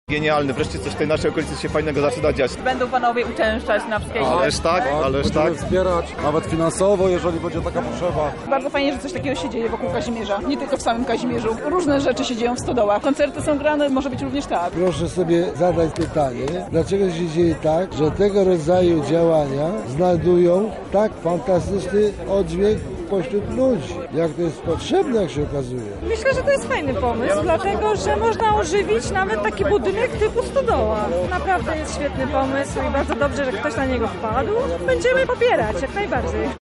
Zapytaliśmy okolicznych mieszkańców, co sądzą o nowej artystycznej inicjatywie.